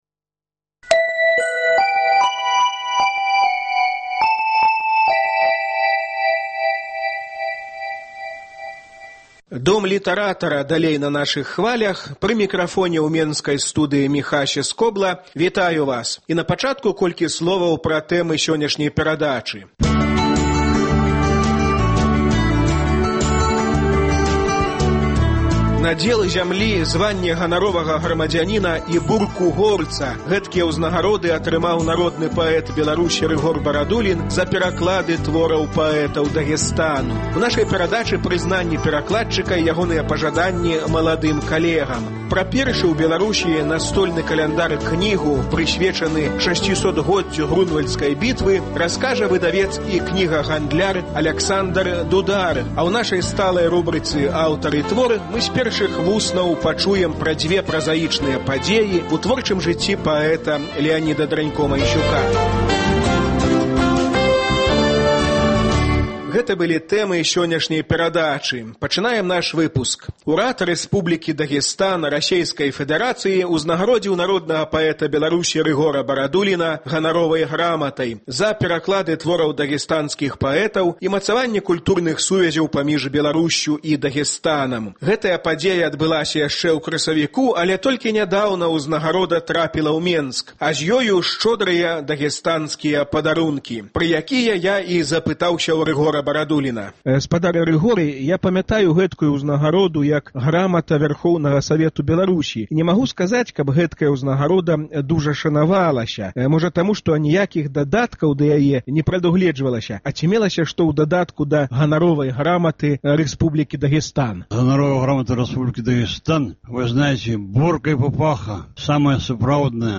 І на заканчэньне Леанід Дранько-Майсюк прачытае свае новыя вершы.